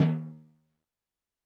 Drums_K4(47).wav